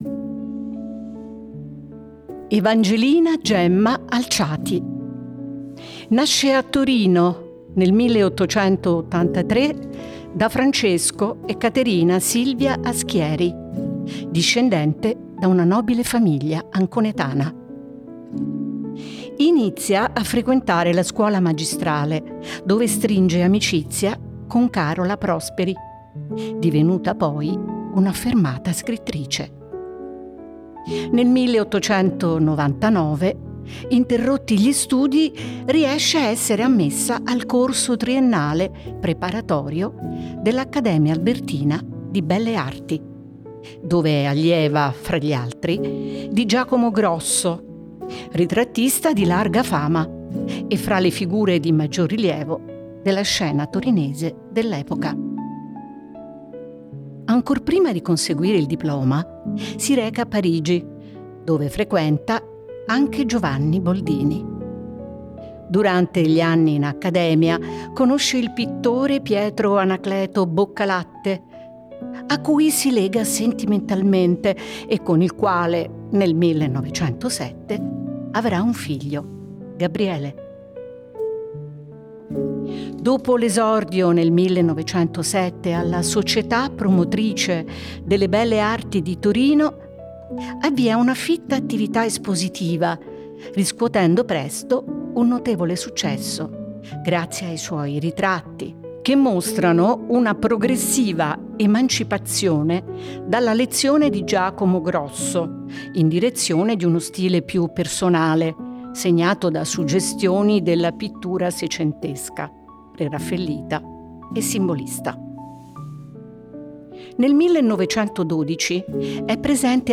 La voce narrante questa volta è femminile ed è vagamente e piacevolmente retrò, possiede il ritmo e la profondità di un’epoca che, per quanto ampia – dagli anni Dieci agli anni Cinquanta del secolo scorso – ci rimanda a un’esistenza autenticamente privata, aliena dalla costante e invasiva condivisione contemporanea.